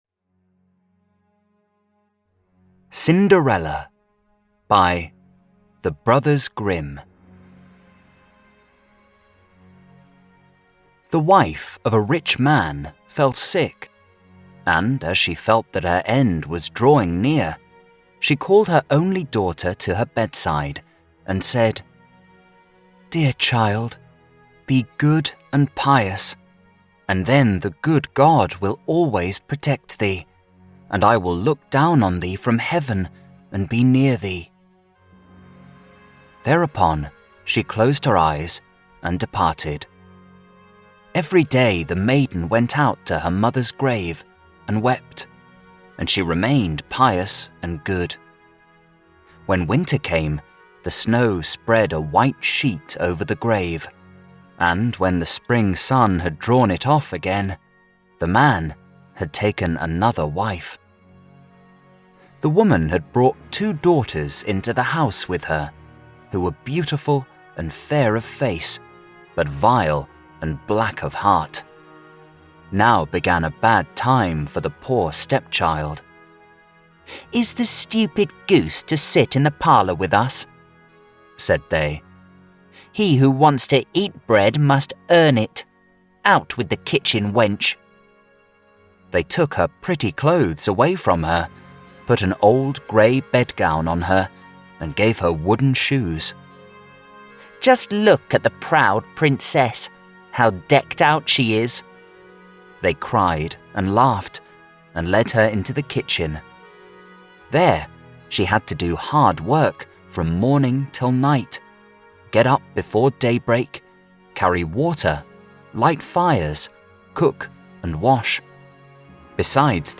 Start Today: Stop Overthinking and Take Action (Audiobook)